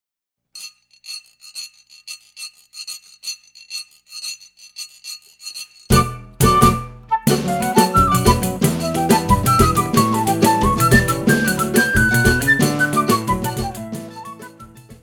Choro brasileiro